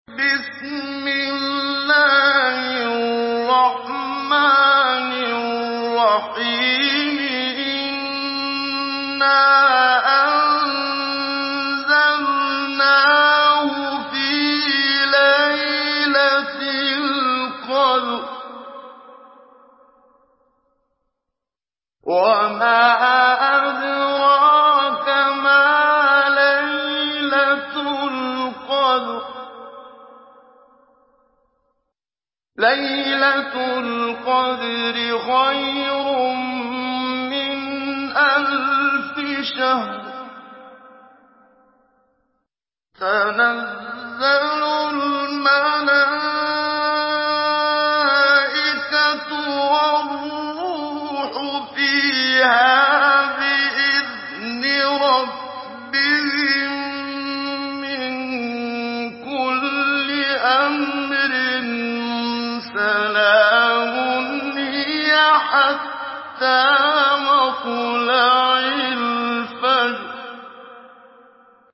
Surah Al-Qadr MP3 in the Voice of Muhammad Siddiq Minshawi Mujawwad in Hafs Narration
Surah Al-Qadr MP3 by Muhammad Siddiq Minshawi Mujawwad in Hafs An Asim narration.